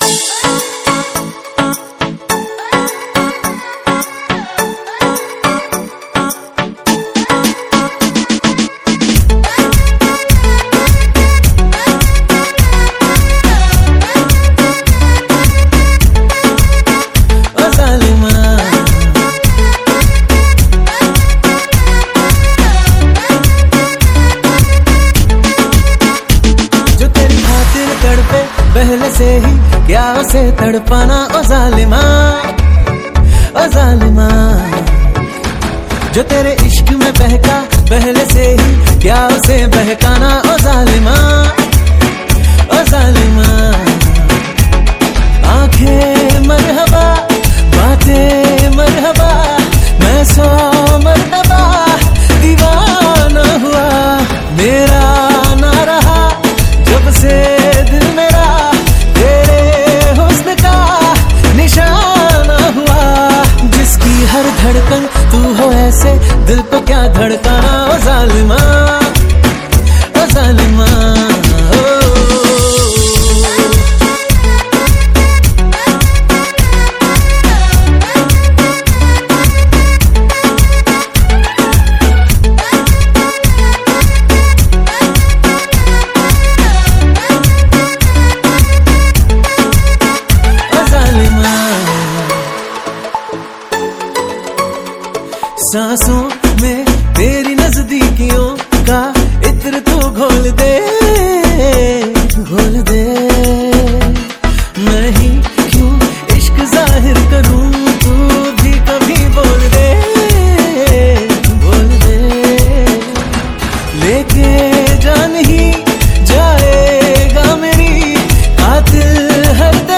Hindi Dj Remix songs